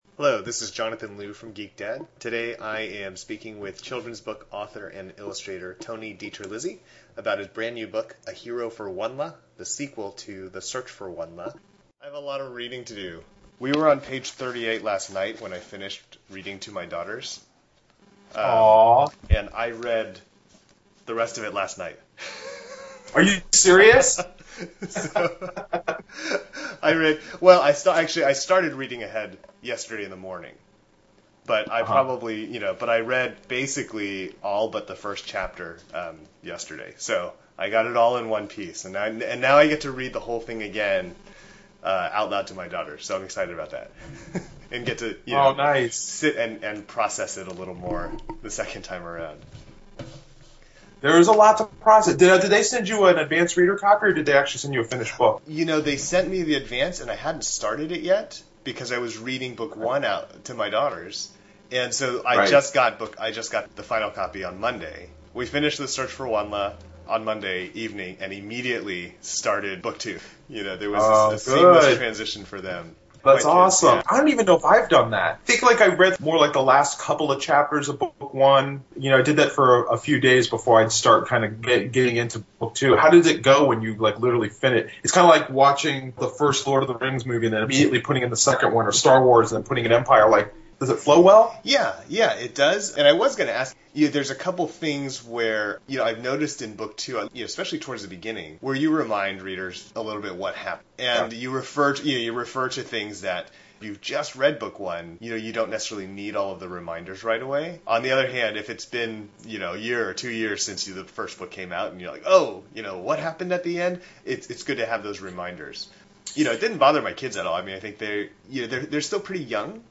GeekDad Interview: Author-Illustrator Tony DiTerlizzi
I spoke with DiTerlizzi over Skype last week just before his tour began, and had a fun conversation about the WondLa series, being dads, technology and nature, and some of his sillier books.
(And apologies for the microphone fumbling sounds, and the poor intro-outro: we started talking right away and I never did an “official” beginning to the interview, and then after we finished and I stopped the recorder we ended up talking a bit longer about board games and parenting …)